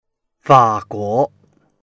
fa3guo2.mp3